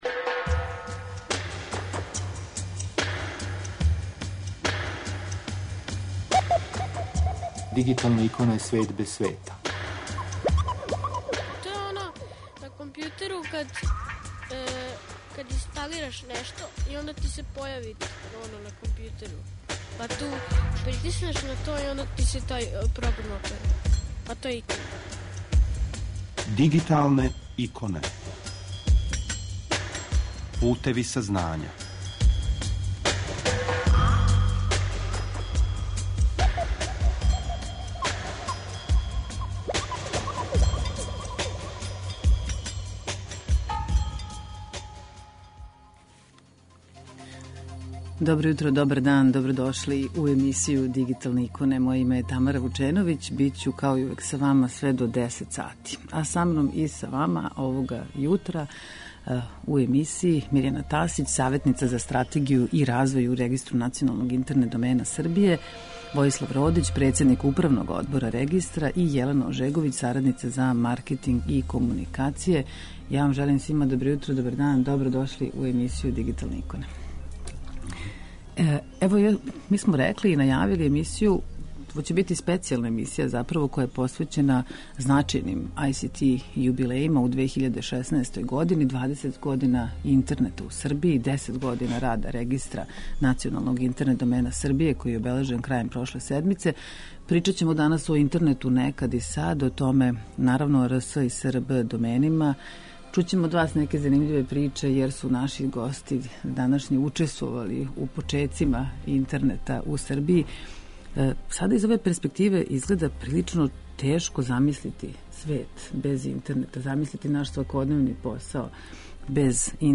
Слушамо специјалну емисију посвећену значајним ICT јубилејима у 2016. години: 20 година интернета у Србији и 10 година рада Регистра националног интернет домена Србије (РНИДС-а), који је обележен крајем прошле седмице.
Са нама уживо